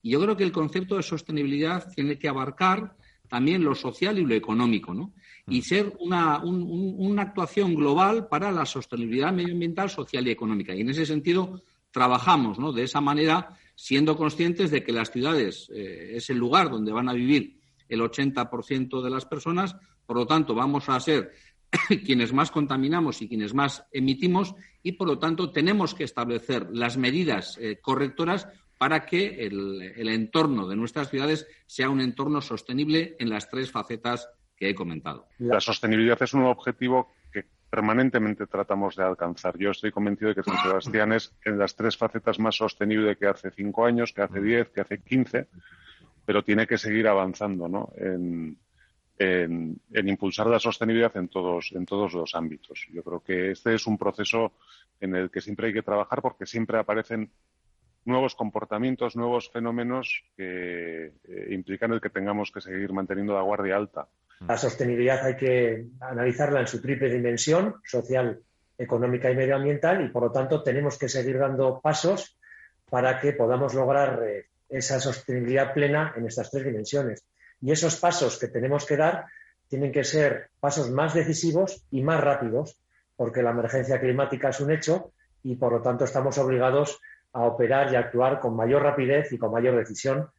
Nuestros alcaldes hablan sobre la sostenibilidad: "Tenemos que seguir dando pasos y avanzando"
Especial en Onda Vasca sobre la sostenibilidad y los retos del futuro con Juan Mari Aburto, Eneko Goia y Gorka Urtaran